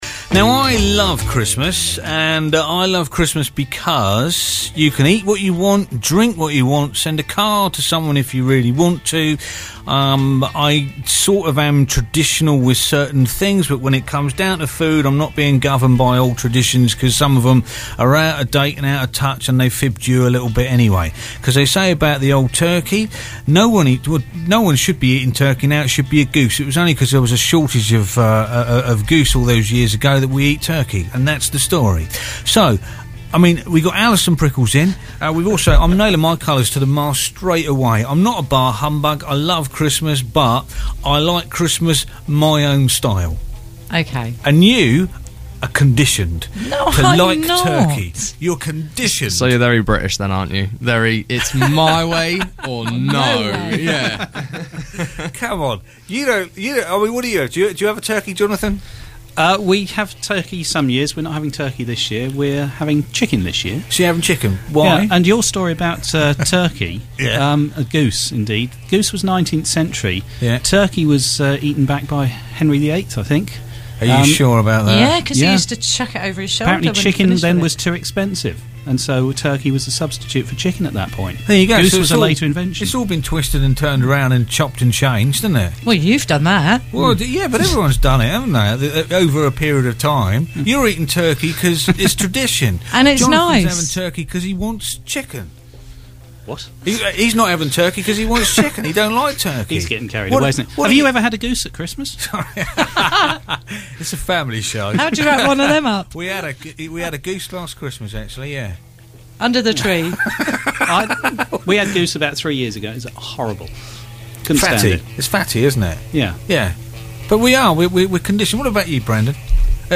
The Monday Phone In Grumpy Christmas